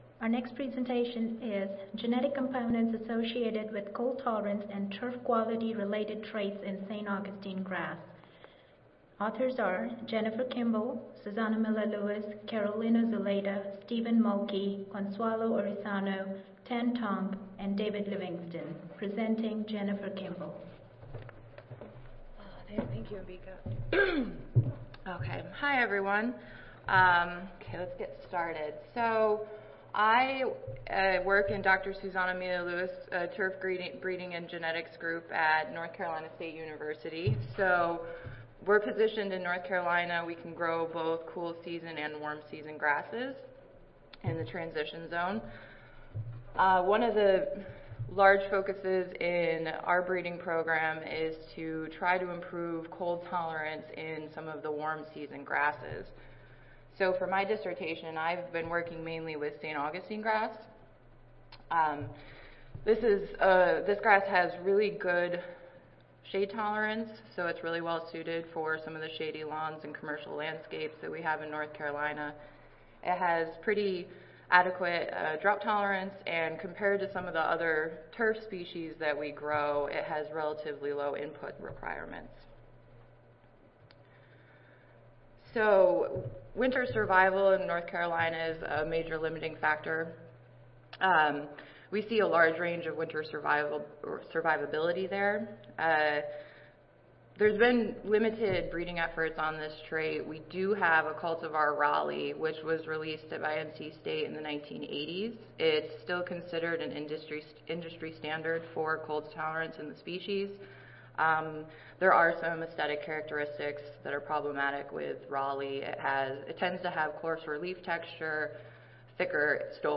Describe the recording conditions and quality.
Session: Graduate Student Oral Competition: Turfgrass Breeding and Genetics, Stress Tolerance (ASA, CSSA and SSSA International Annual Meetings (2015))